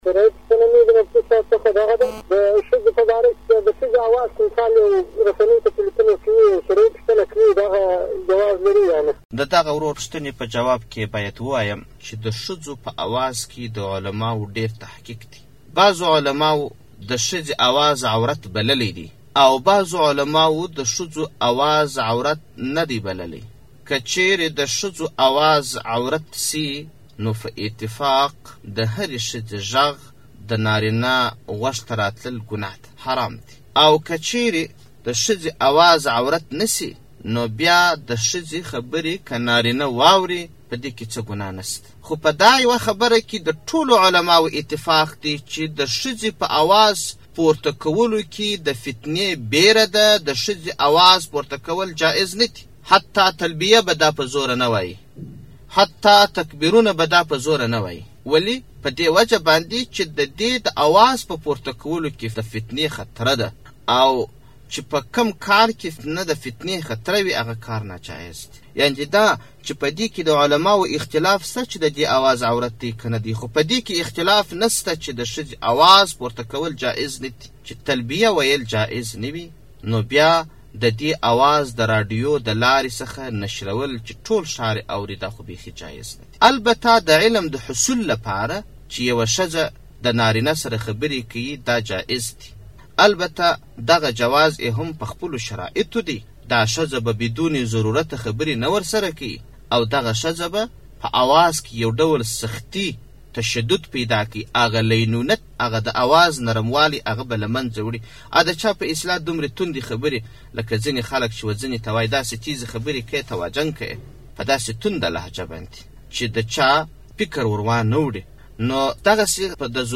ږغیزي شرعی پوښتني او جوابونه